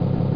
1 channel
engine2.mp3